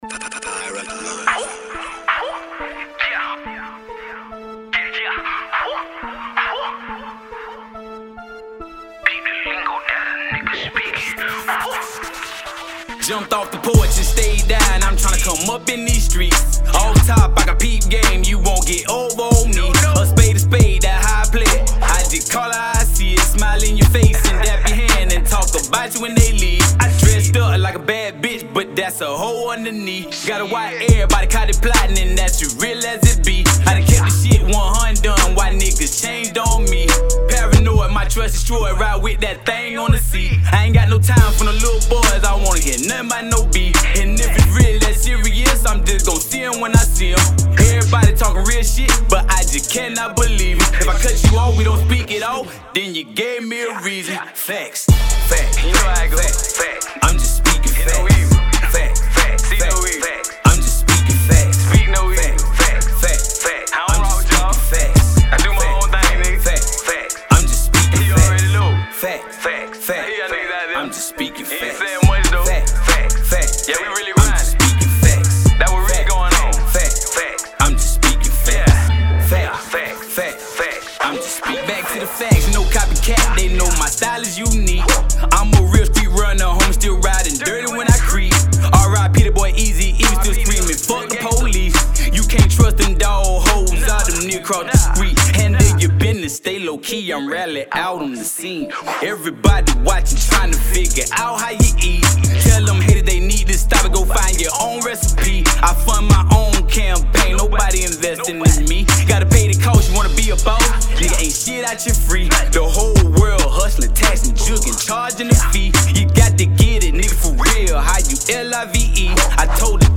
Hiphop
Trending & Catchy